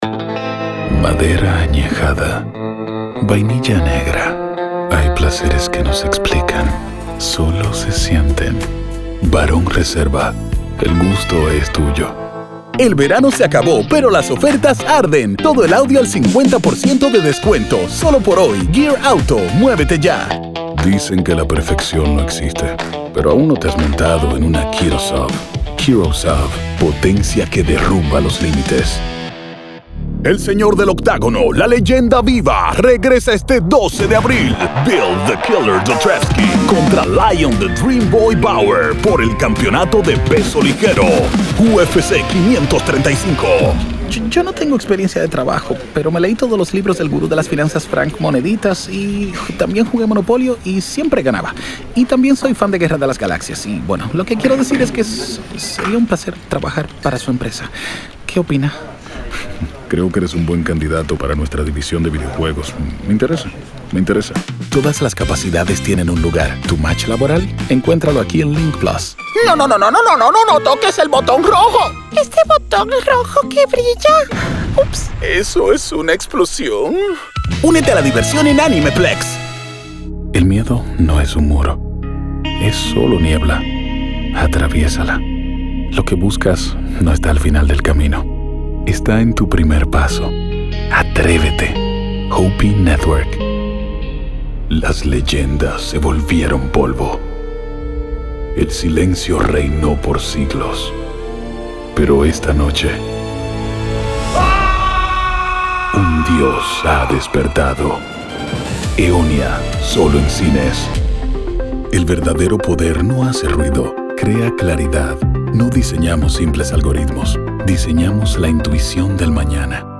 2026 Español Demo De Locucion
English latino, español dominicano, español neutro.
Demo De Voz 2026.mp3